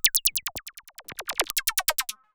Glitch FX 14.wav